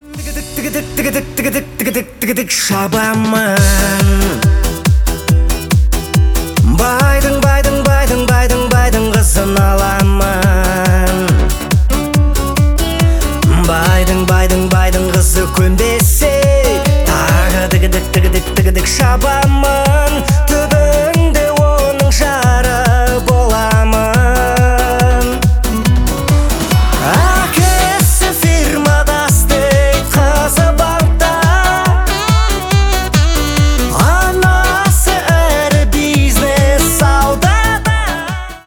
Поп Музыка
весёлые # ритмичные